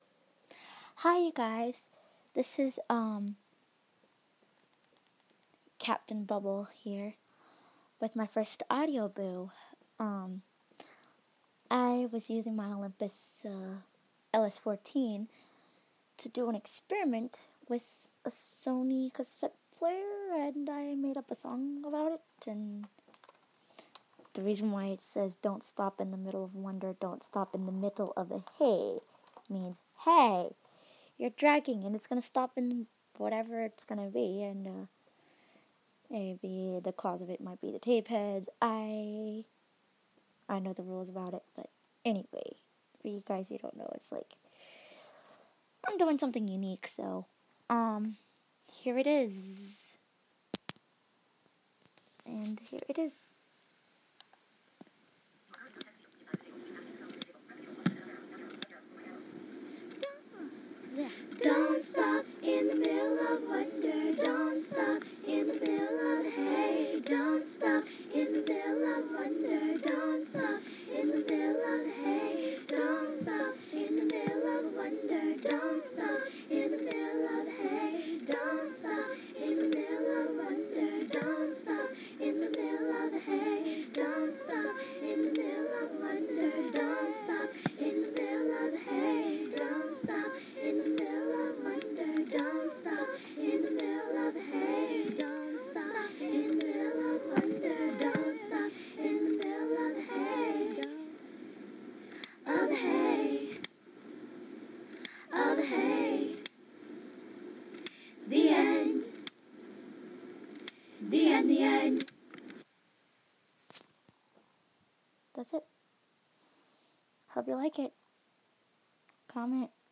My Absolute first audio boo with a unique song I made up using a Sony Walkman when the tape is trying to play but it could not
It is a Sony Walkman making the clicking sound as the beat while I made a somewhat of everyday objects.